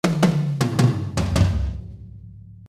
Mit Evenbtide Tverb (und einem Gate auf den Raummikros):